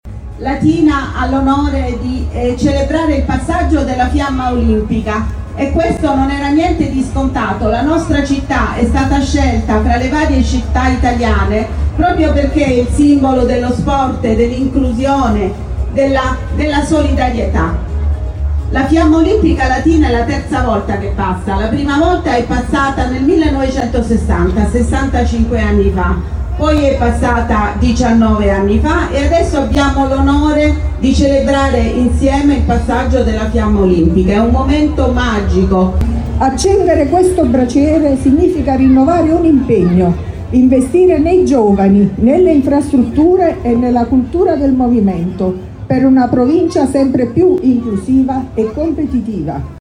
Le parole del sindaco Celentano e del Prefetto Ciaramella dal palco di Piazza della Libertà